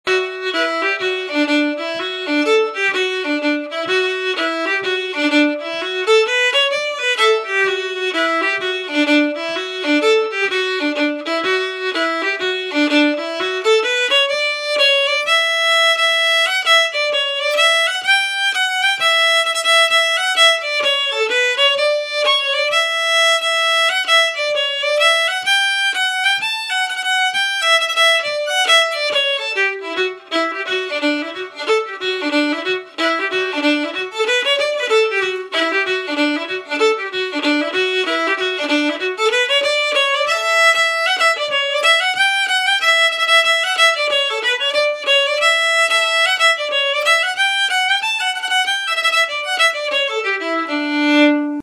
Key: D
Form: Reel
Played slowly, then up to tempo